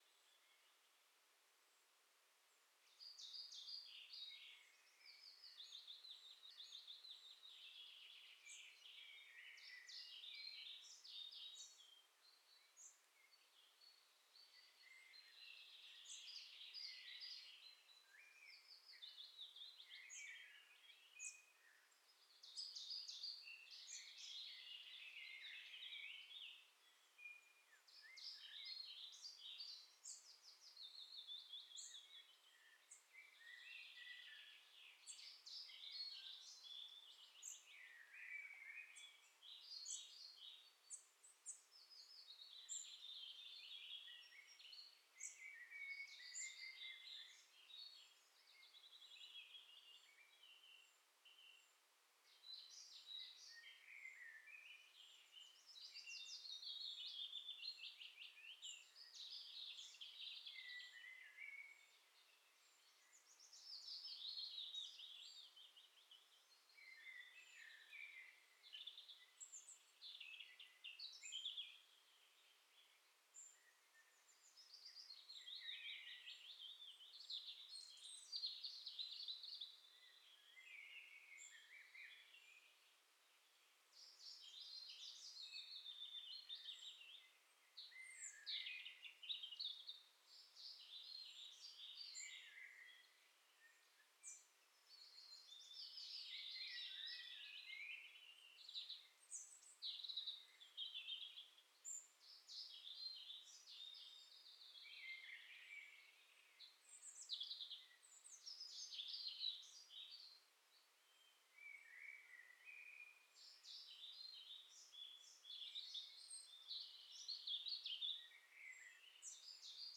Songbirds Dawn Chorus. Early Spring Ambience, No Wind. Clean 20-Minute Seamless Loop.
Dawn Chorus in Early Spring – Bird Ambience